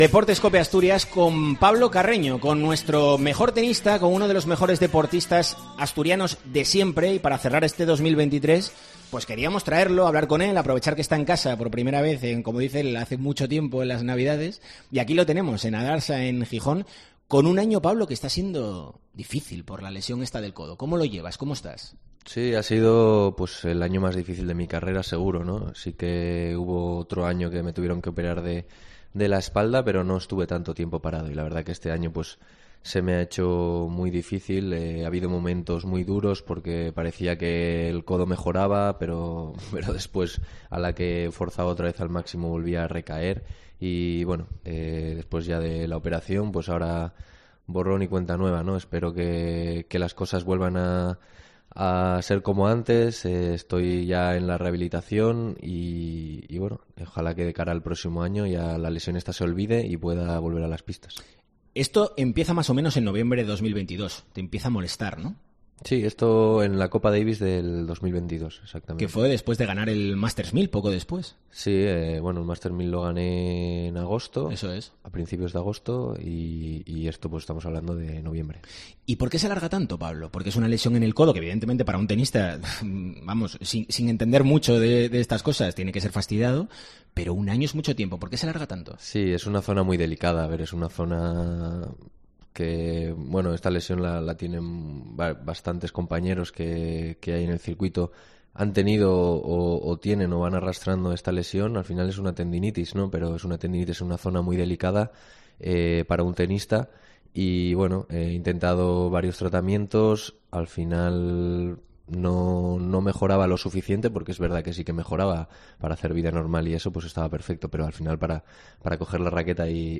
Es el protagonista en Deportes COPE Asturias para cerrar el año desde ADARSA .